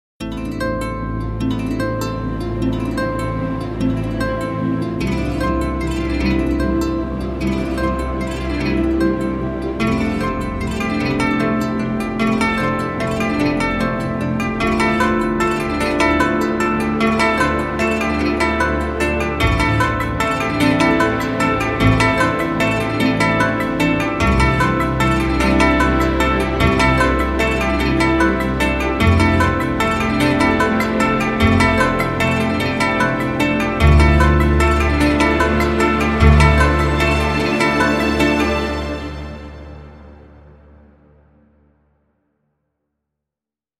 这种乐器的声音非常简单，但又很有异域风情，可以和其他凯尔特弦乐器搭配使用，增加你的音乐库的多样性。这款库包含了 6 层力度， 轮循环，3 种音色位置，预录制的上下琶音和 5 种打击音效，可以实现很多创意。
拉伸质量非常高，你不会听到任何人工的声音，也不会在使用全音域时有任何问题。
这款乐器使用了两个麦克风采样，还有一个噪音层（随机添加一些划弦或演奏者的噪音），可以随时关闭。